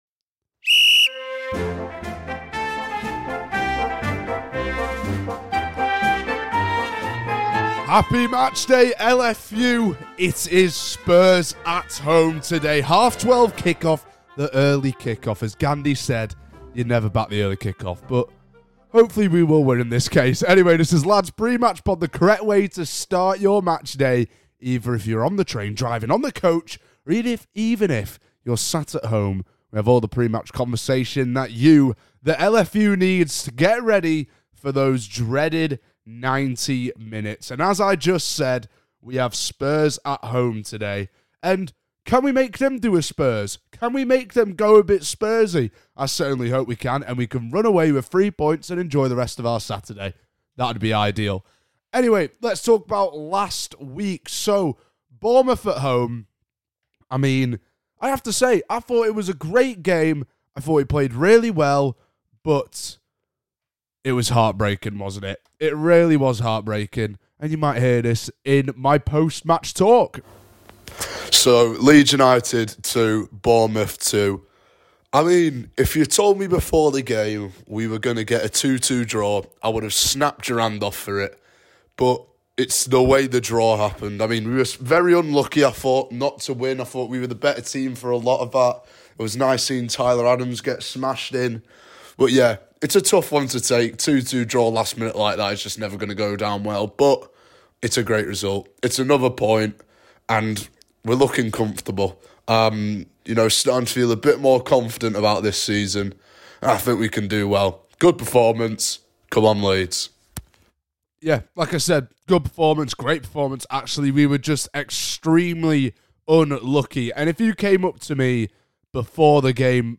L2L IS BACK but no Dad just Lad this time…